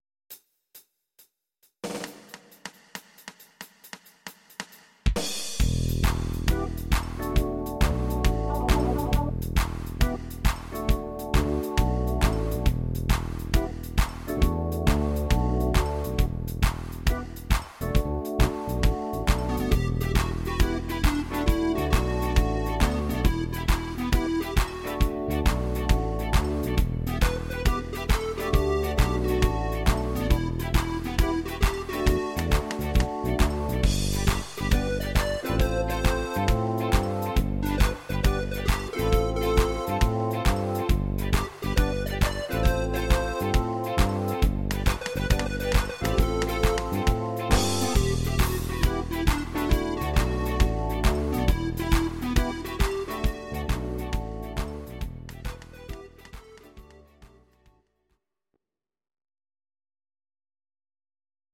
Audio Recordings based on Midi-files
Our Suggestions, Pop, German, Ital/French/Span, 1980s